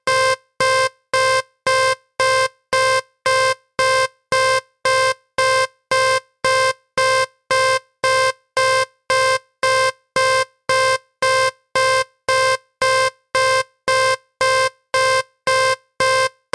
Alarm 2
Tags: sound fx boxed in